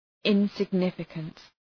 Shkrimi fonetik{,ınsıg’nıfəkənt}
insignificant.mp3